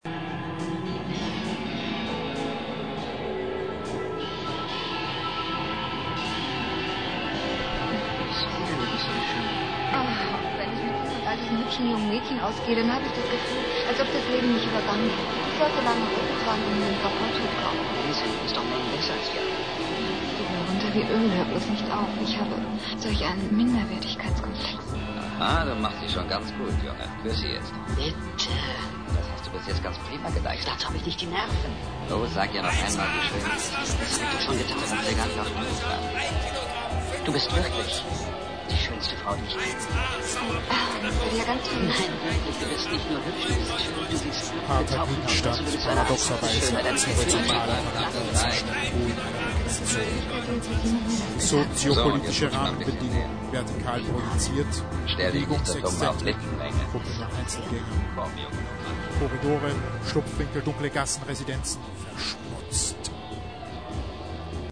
Schloss Lüntenbeck 2002